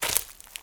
STEPS Leaves, Walk 14.wav